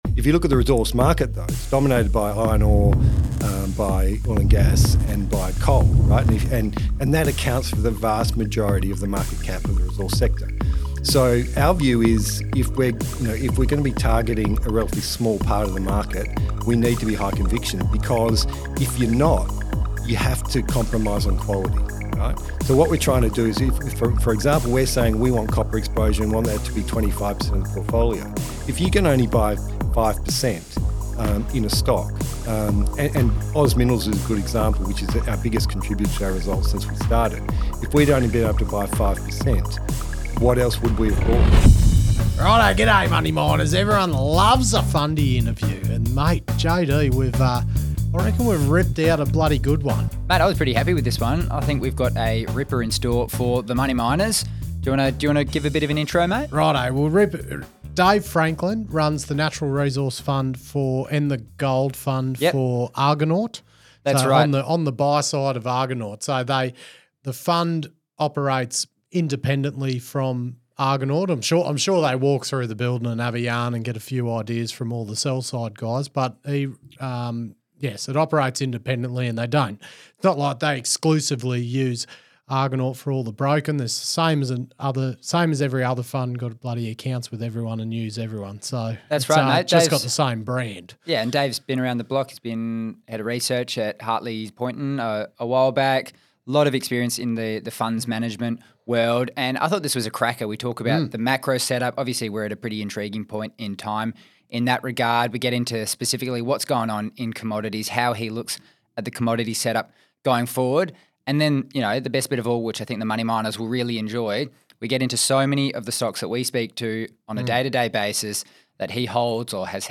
Today we sat down for a cracking chat